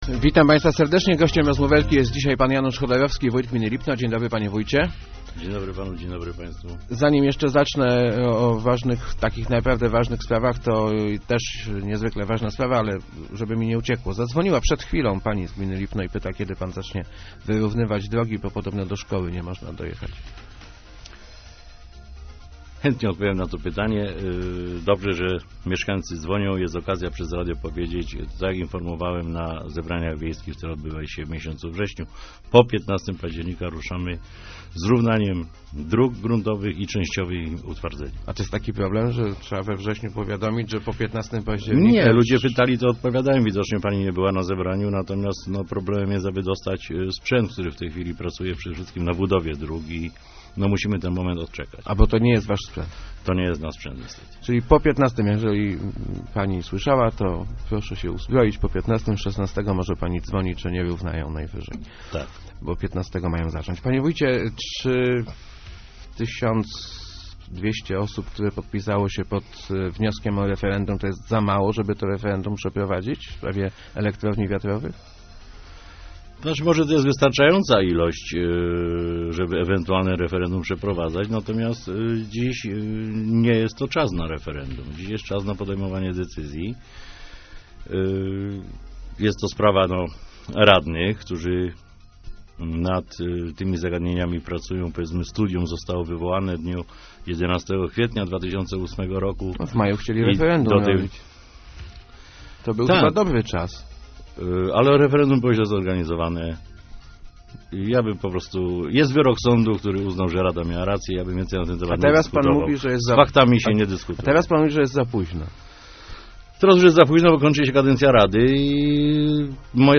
Umieszczę elektrownie wiatrowe na swoich materiałach wyborczych - zapowiedział w Rozmowach Elki wójt gminy Lipno Janusz Chodorowski. Jego zdaniem turbiny oznaczają wielką szansę dla gminy.